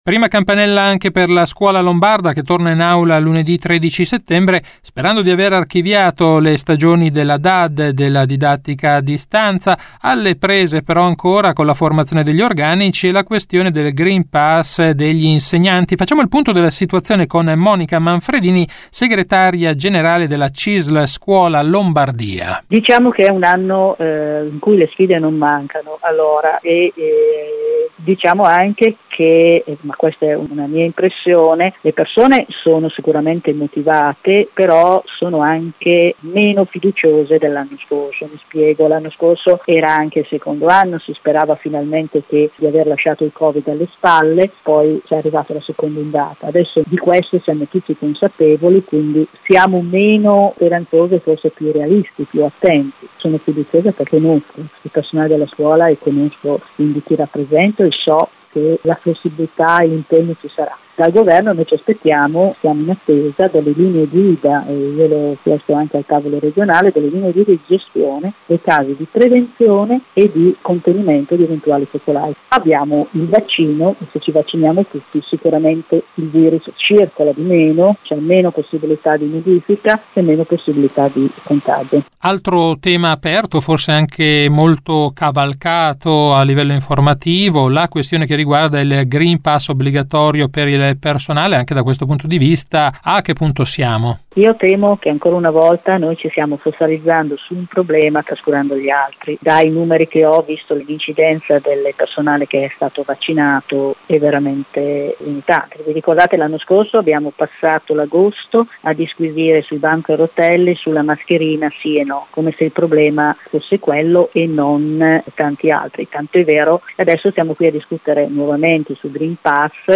Di seguito la puntata del 10 settembre di RadioLavoro, la rubrica d’informazione realizzata in collaborazione con l’ufficio stampa della Cisl Lombardia e in onda ogni quindici giorni su RadioMarconi il venerdì alle 12.20, in replica alle 18.10.